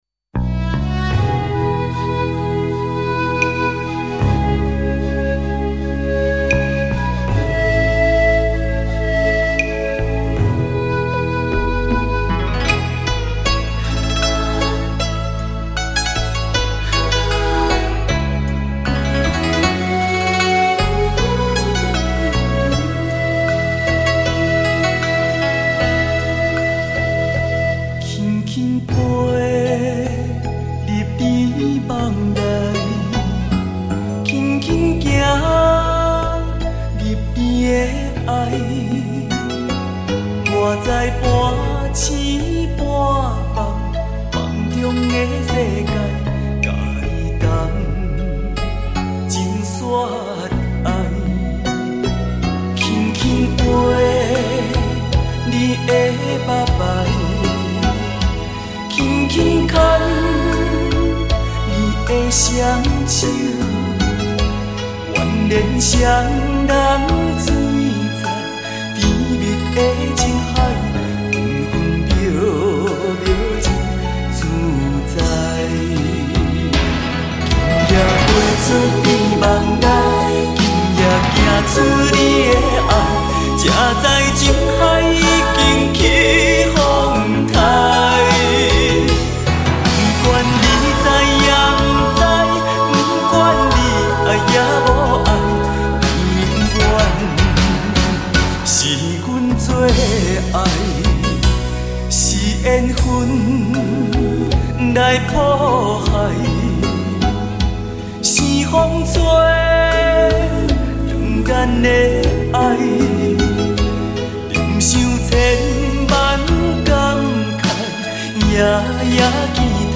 他以浑圆的嗓音、纯熟的歌唱技巧